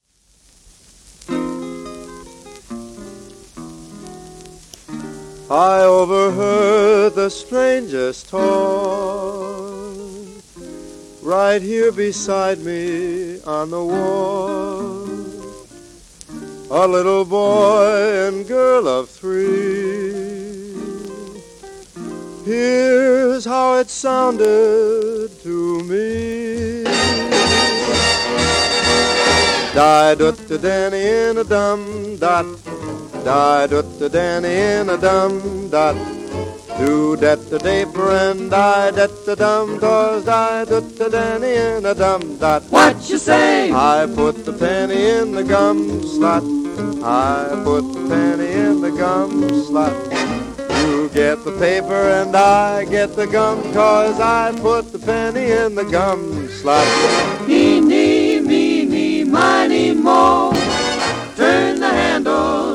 盤質A- *小キズ、薄いスレ